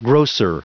Prononciation du mot grocer en anglais (fichier audio)
Prononciation du mot : grocer